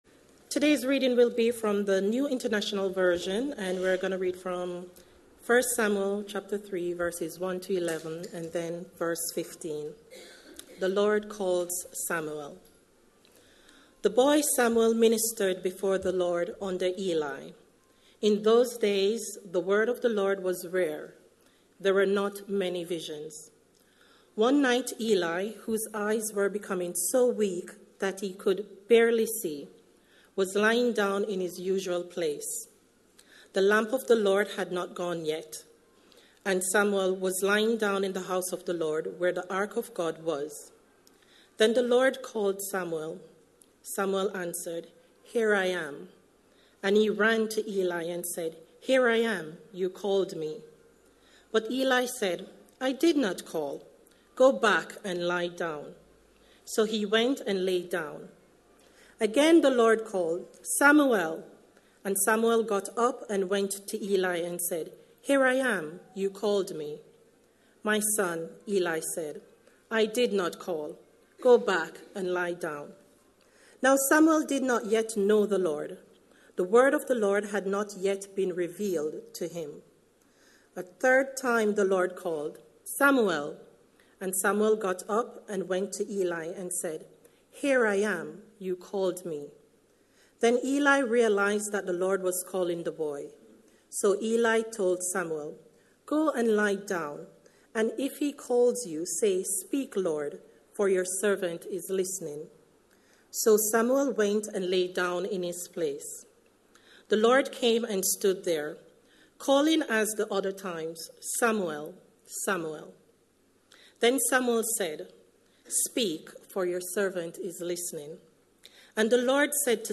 A sermon preached on 2nd September, 2018.